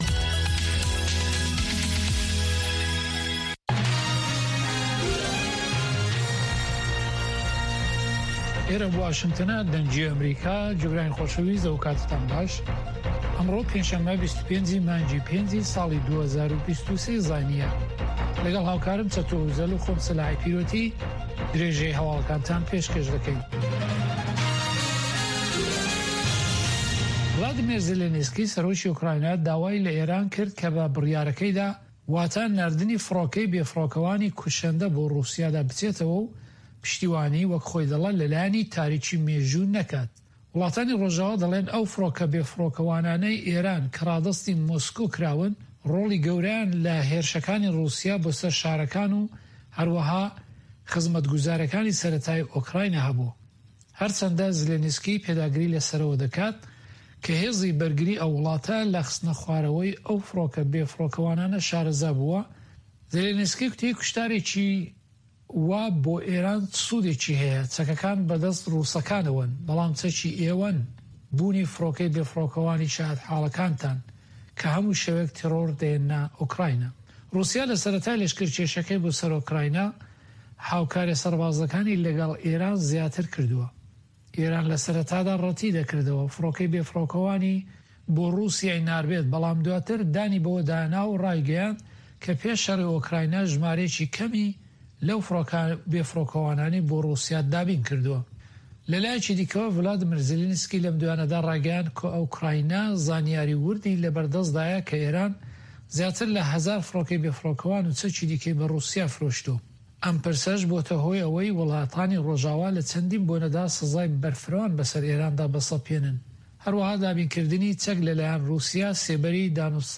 Nûçeyên Cîhanê ji Dengê Amerîka